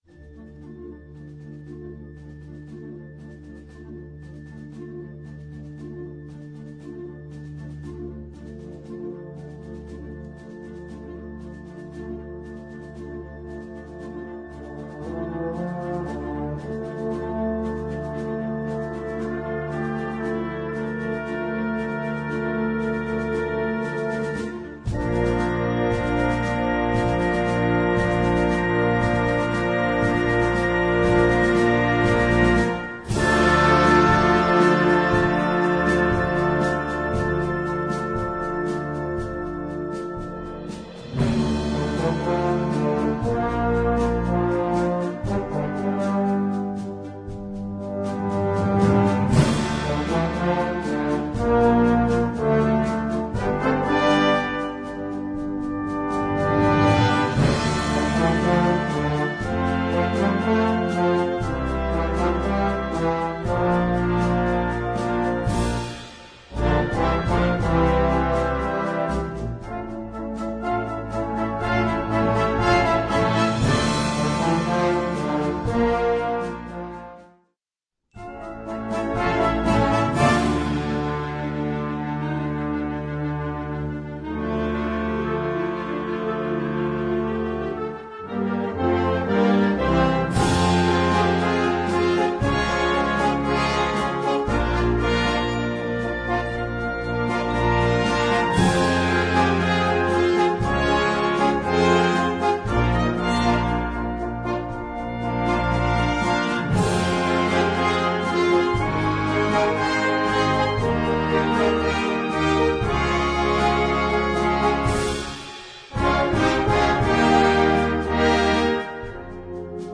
Gattung: Filmmusik
Besetzung: Blasorchester
Arrangement für Blasorchester und Schlagzeug